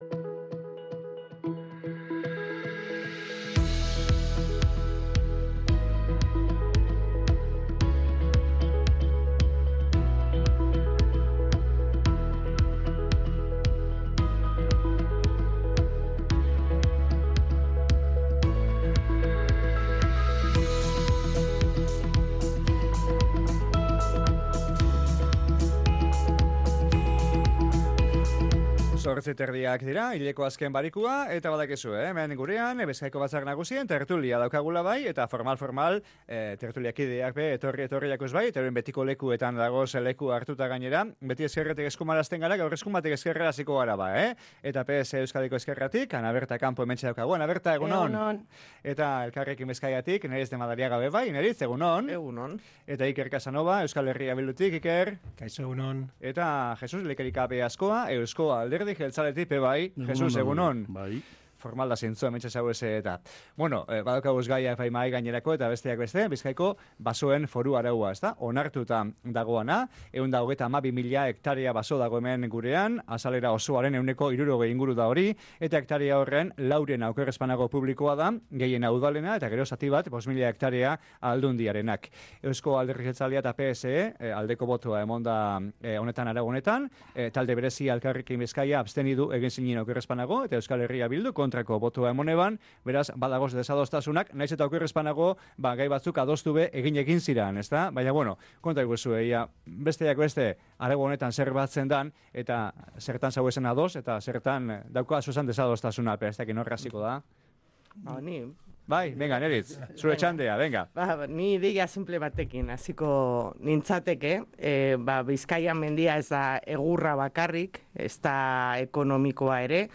Basoen foru araua aztergai Bizkaiko Batzar Nagusien gaurko tertulian | Bizkaia Irratia